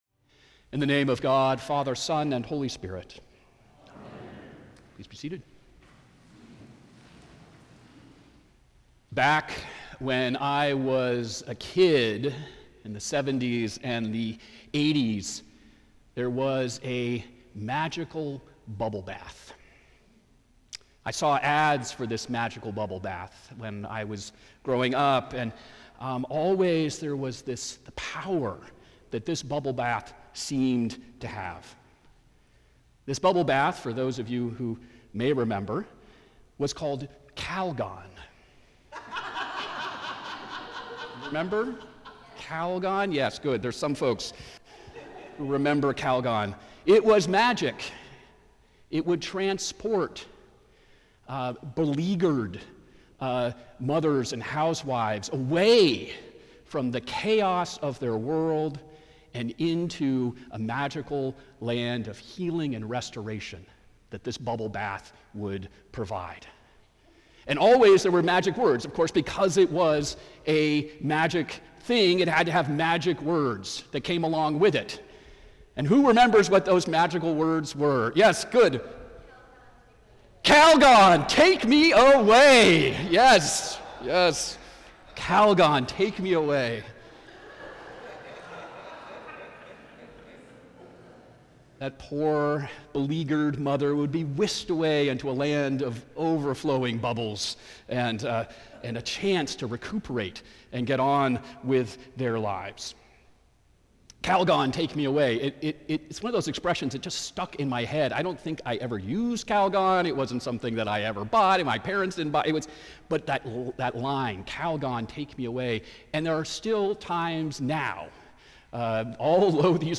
St. Augustine by-the-Sea Sermons